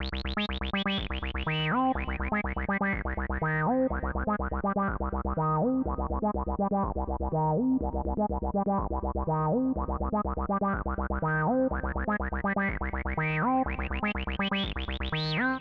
ENE Acid Riff C-Ab-G-Eb.wav